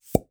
New grunk collection SFX
pop.wav